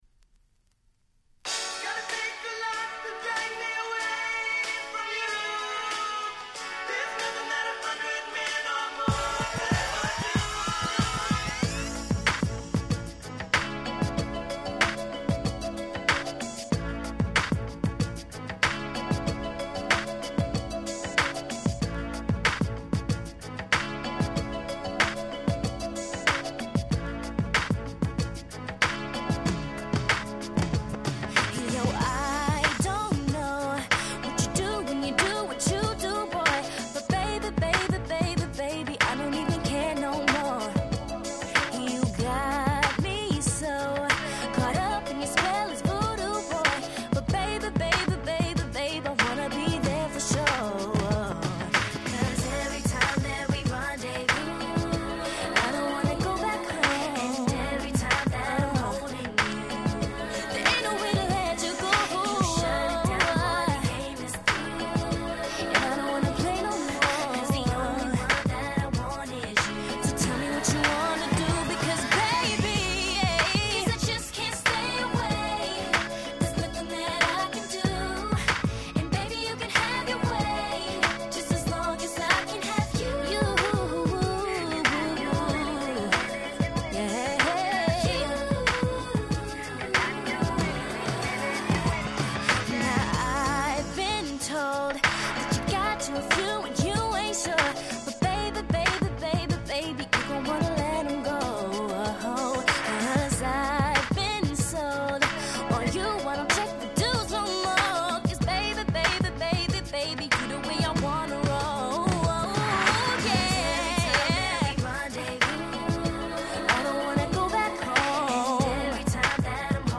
06' Smash Hit R&B !!